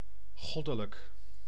Ääntäminen
Ääntäminen : IPA : /dɪ.ˈvaɪn/ US : IPA : [dɪ.ˈvaɪn]